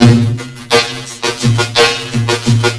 Lmstrch.wav Timestretched BreakBeat 31k